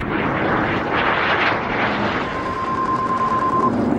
• WINDY BEEPS.wav
WINDY_BEEPS_Wdt.wav